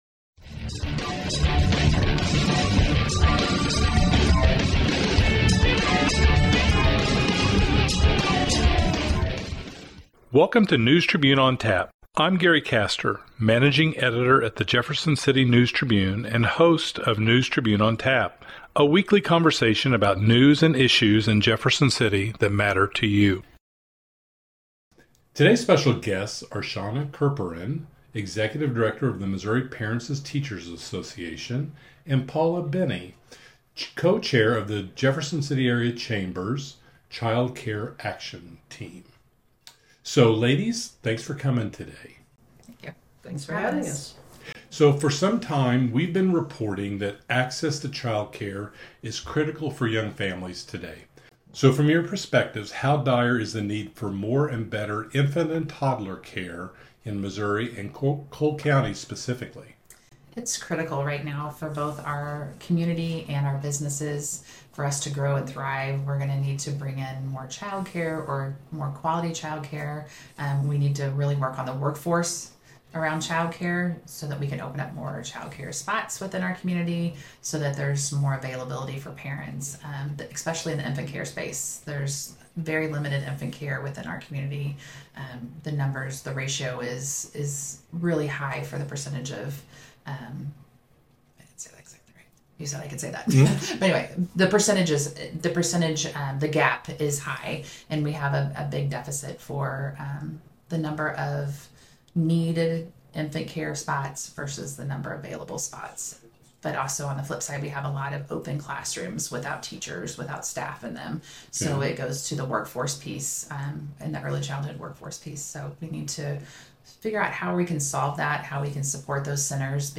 The conversation